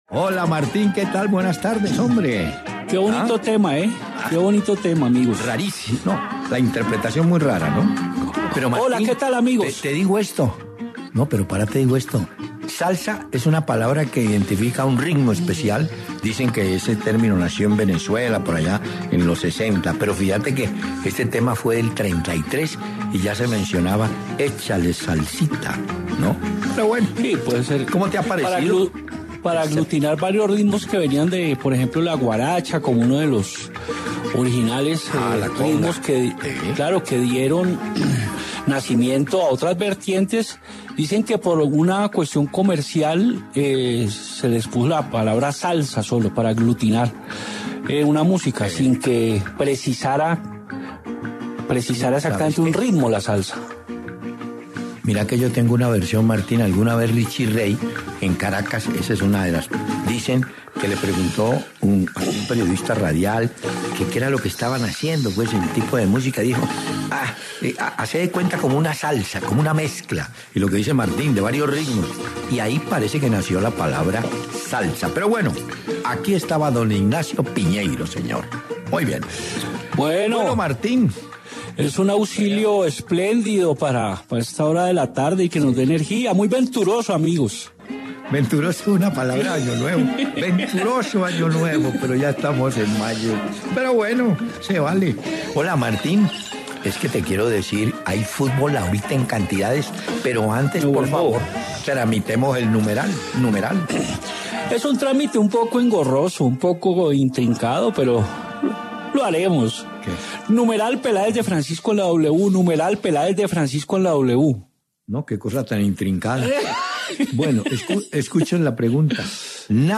Hernán Peláez y Martín de Francisco conversaron sobre la última fecha del todos contra todos en el FPC.